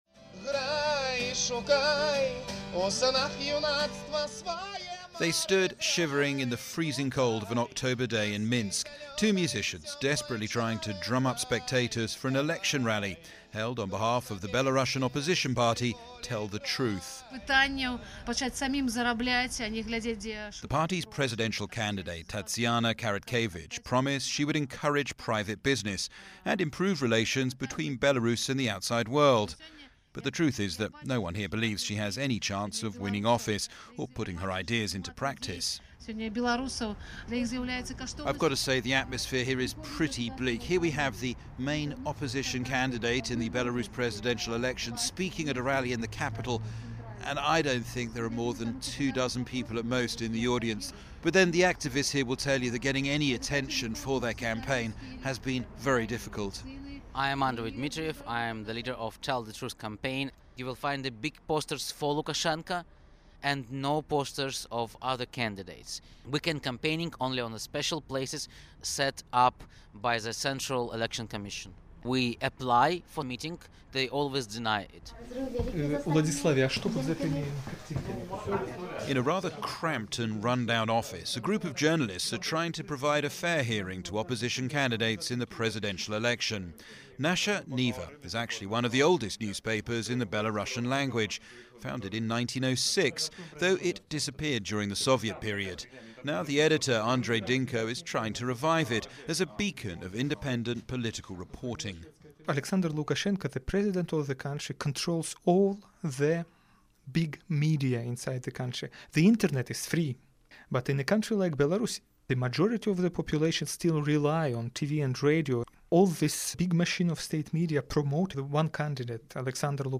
So is this a fair contest? My report for BBC Radio.